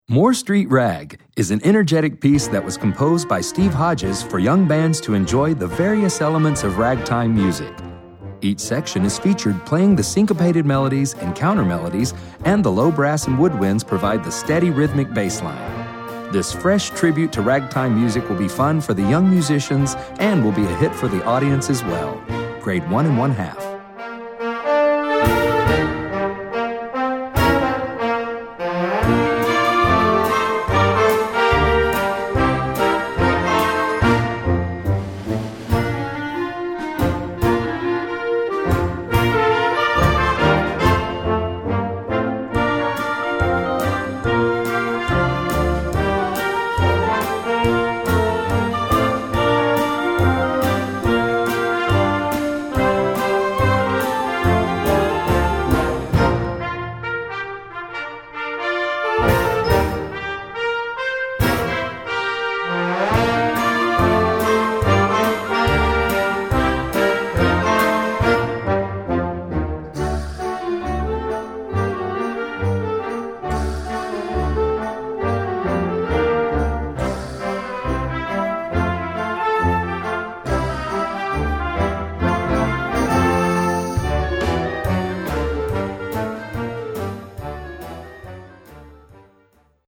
Werk für Jugendblasorchester Schwierigkeit
2:15 Minuten Besetzung: Blasorchester PDF